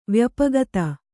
♪ vyapagata